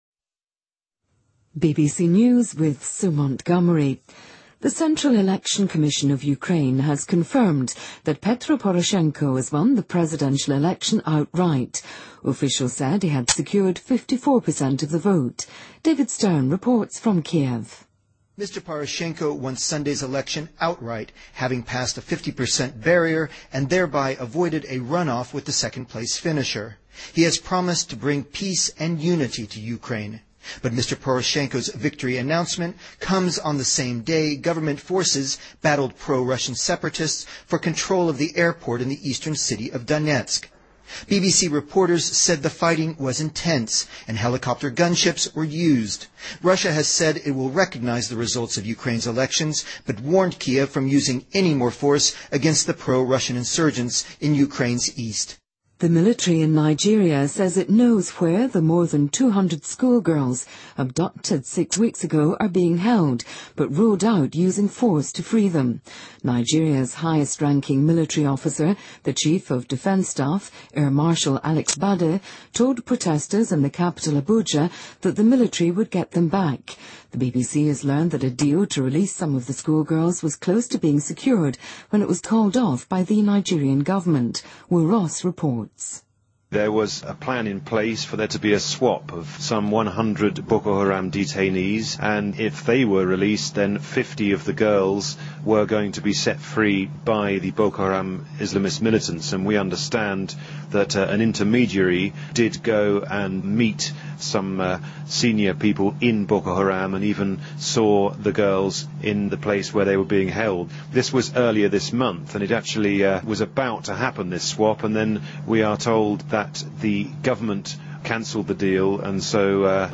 BBC news,尼日利亚军方称已知道失踪女孩所在地